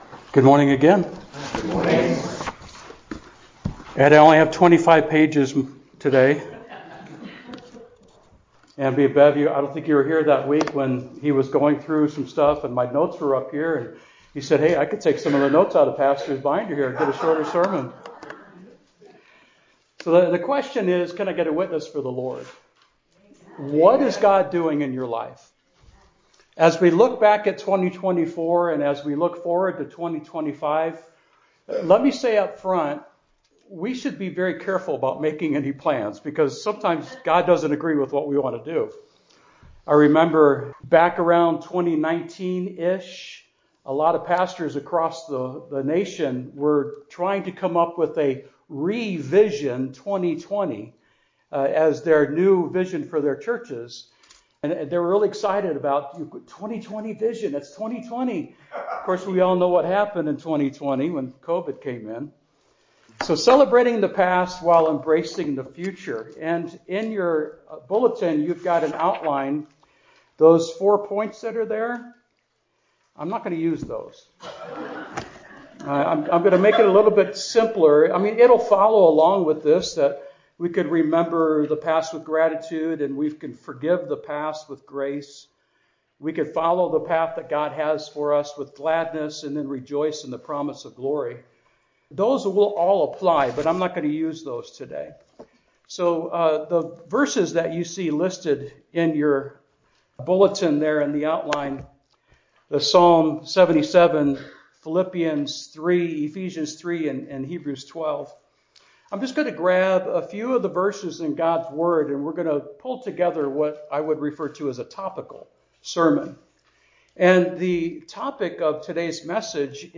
Miscellaneous Messages